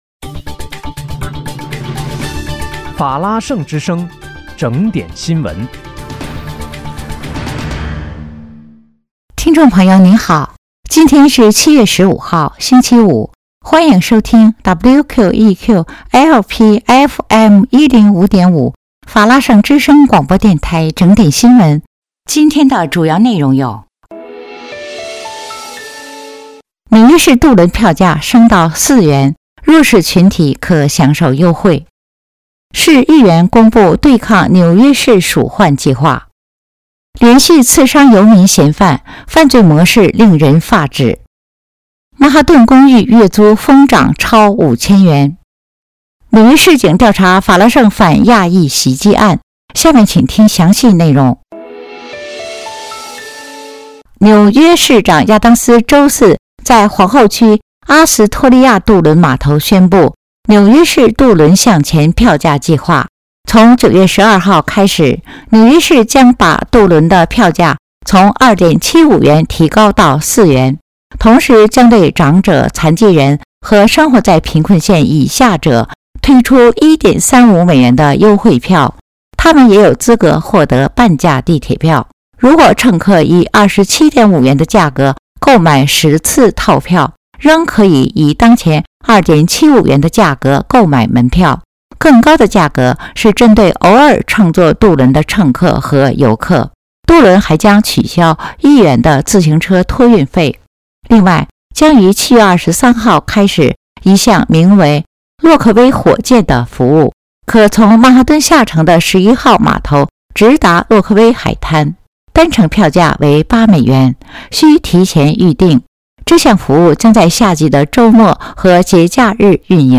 7月15日（星期五）纽约整点新闻
听众朋友您好！今天是7月15号，星期五，欢迎收听WQEQ-LP FM105.5法拉盛之声广播电台整点新闻。